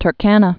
(tər-kănə, tr-känə), Lake also Lake Ru·dolf (rdŏlf)